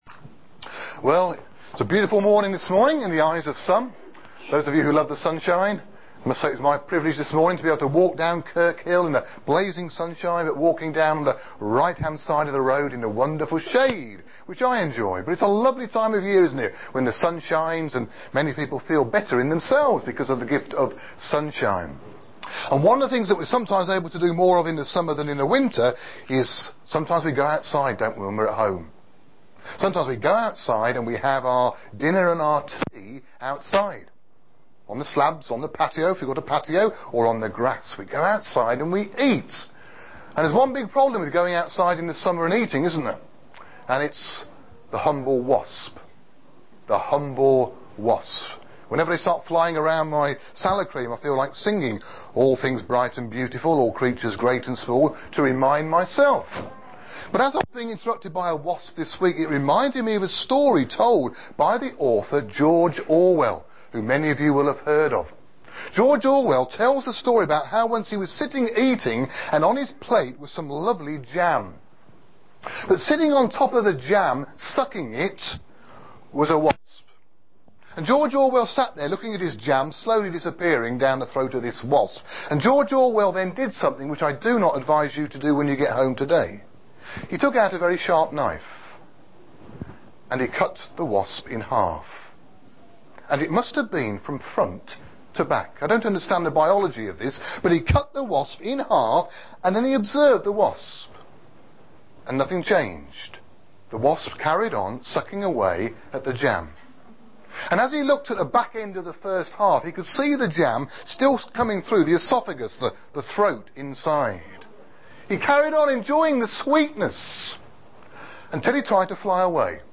Shepshed Evangelical Free Church – Children’s Talks